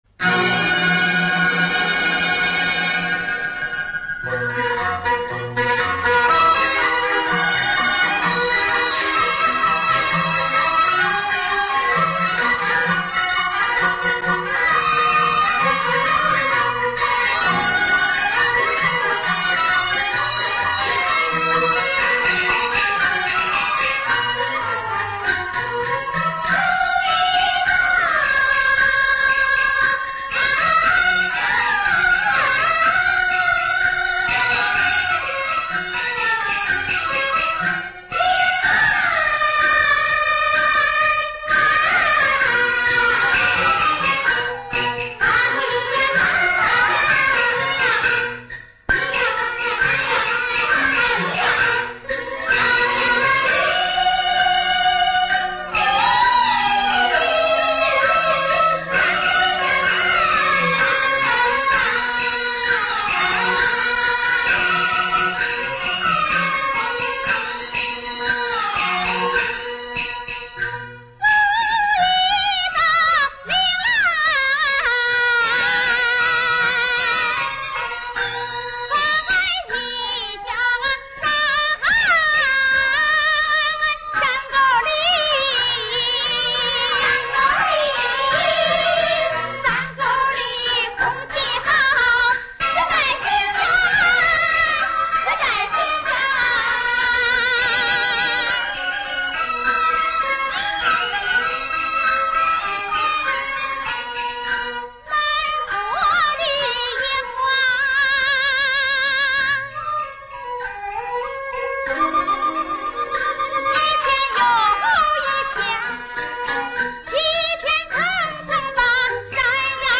电影豫剧